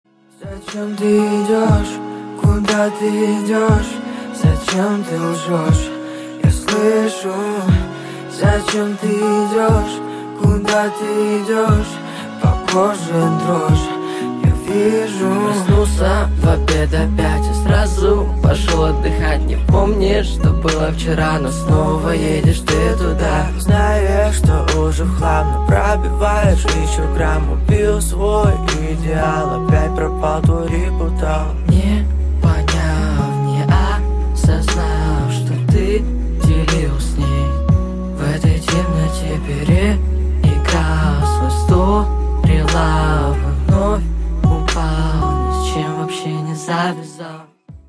поп
мужской вокал
лирика
русский рэп
спокойные
медленные
качающие